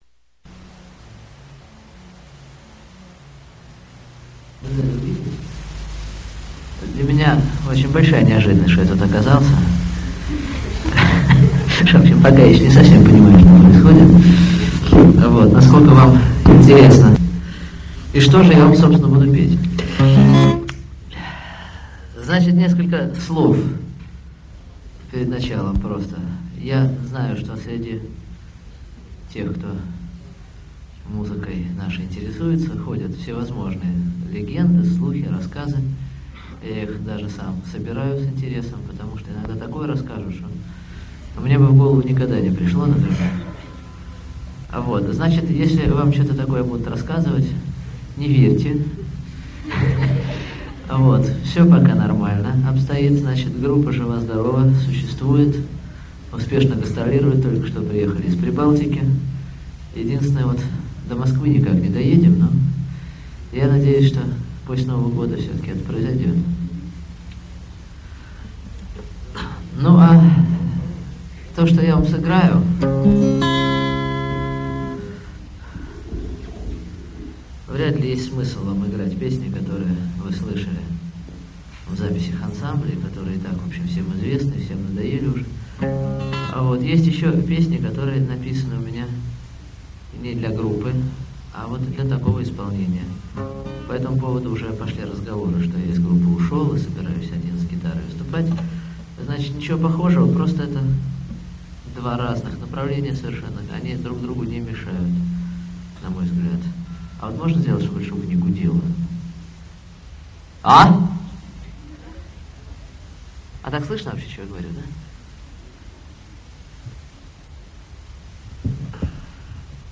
Сольный концерт
в московской школе